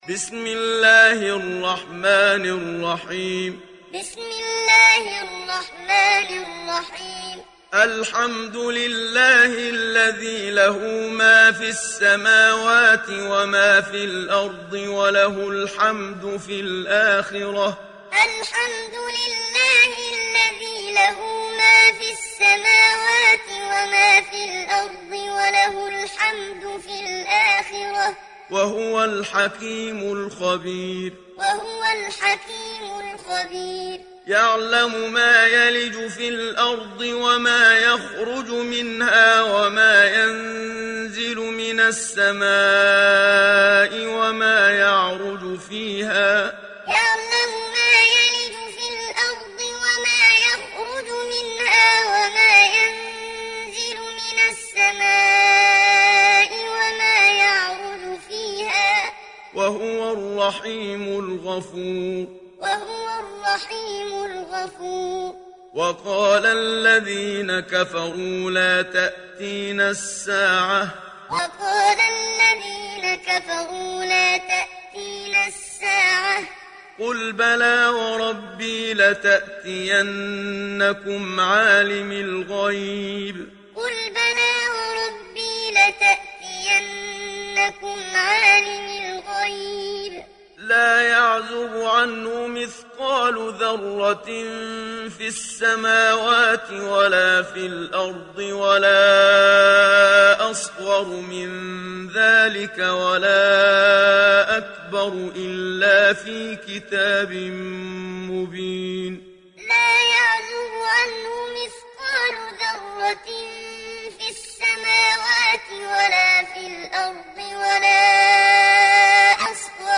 دانلود سوره سبأ mp3 محمد صديق المنشاوي معلم روایت حفص از عاصم, قرآن را دانلود کنید و گوش کن mp3 ، لینک مستقیم کامل